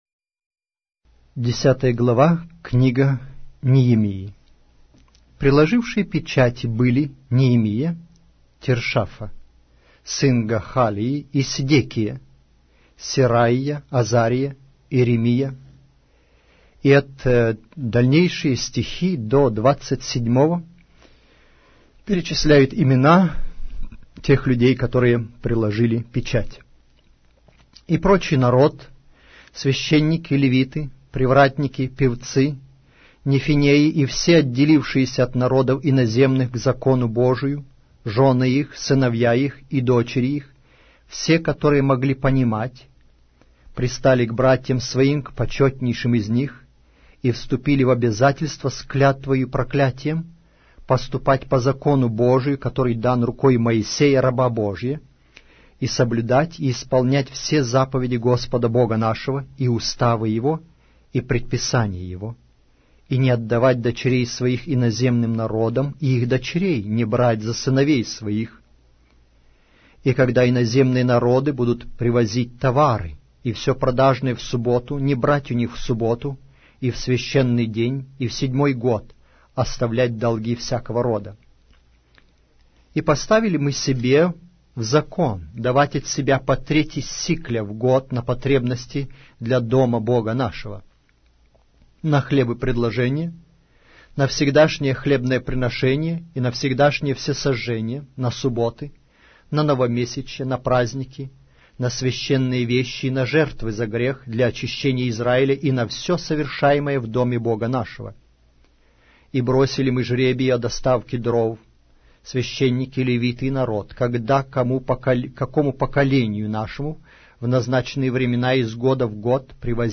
Аудиокнига: Пророк Неемия